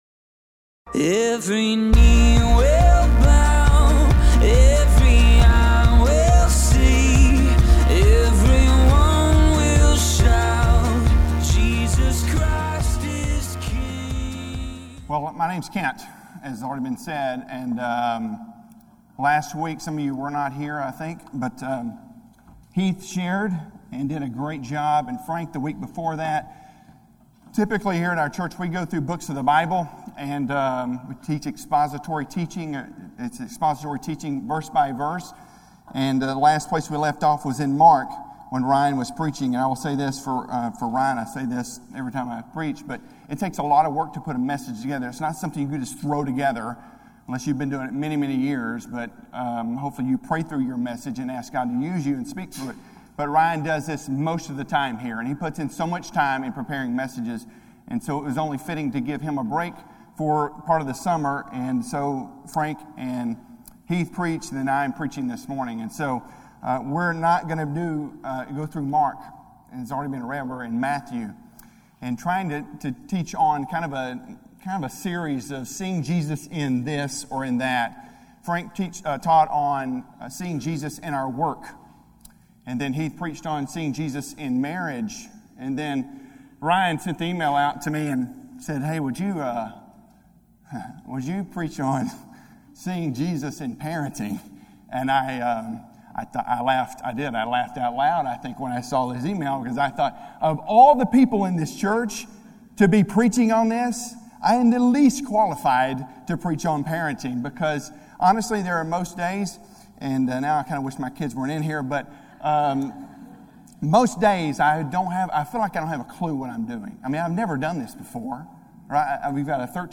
A message from the series "Single Sermon Messages."